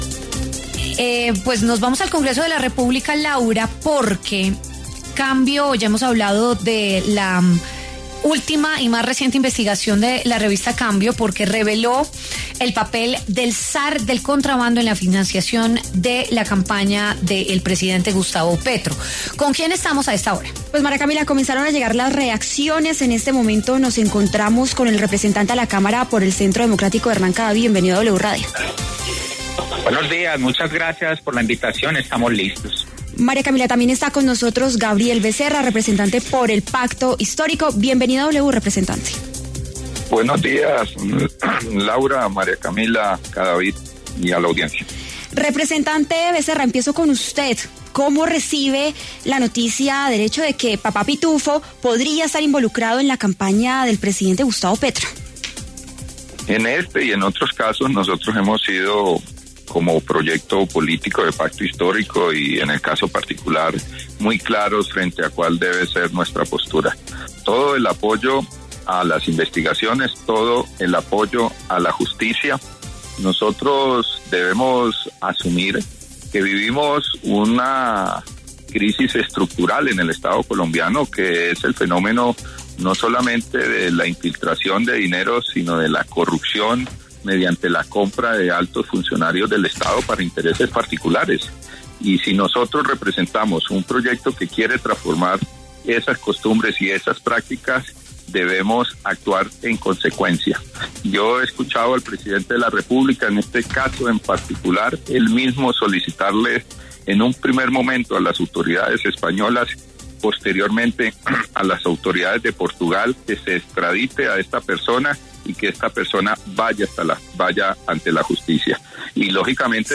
En W Fin de Semana, los congresistas Hernán Cadavid y Gabriel Becerra se refirieron a lo que ‘Papá Pitufo’ podría contar sobre la plata que aportó a la campaña del presidente Gustavo Petro.